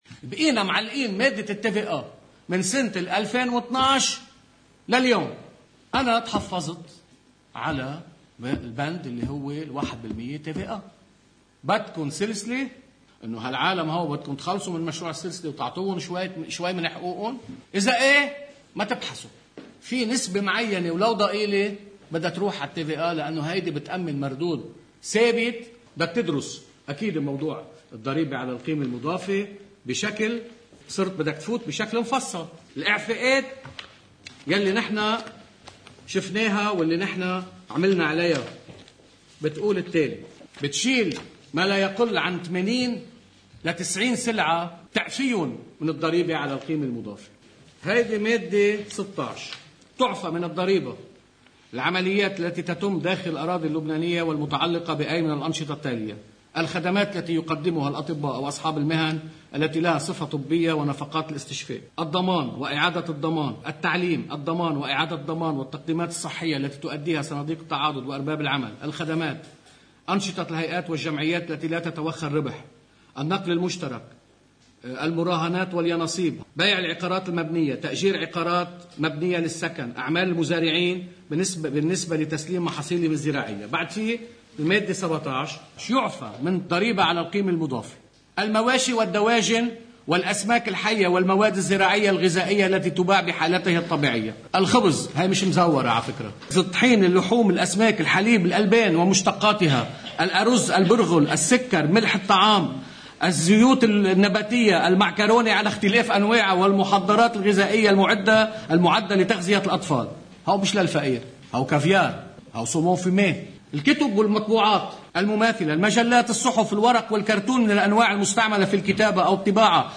مقتطف من حديث رئيس لجنة المال والموازنة النائب كنعان حول مسرحية “الحراك المدني“: (الجزء 2)
ولأن الحقائق التي يكشفها تكتّل التغيير والإصلاح بالعموم واي موقف سياسي لأي قضية محقة، عمد موقعنا على توثيق كلام كنعان بالأمس باللهجة المحكية، للإشارة الى أمرين وتأكيد أنّ :